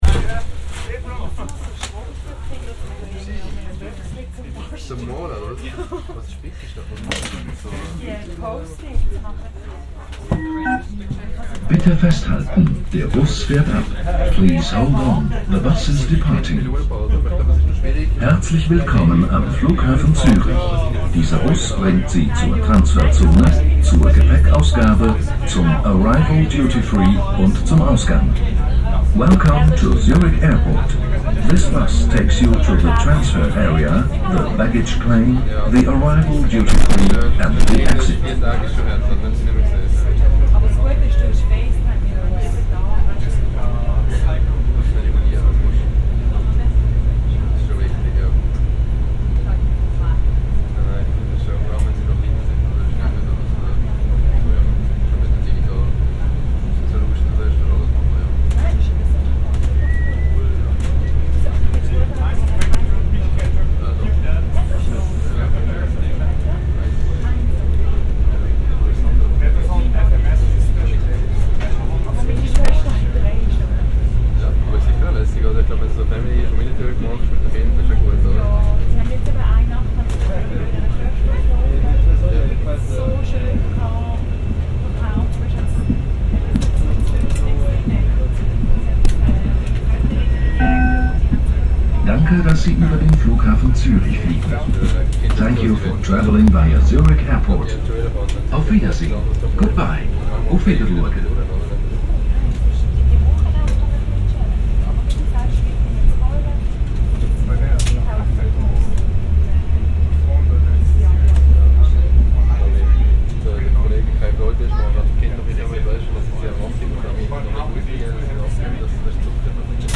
Zurich airport shuttle bus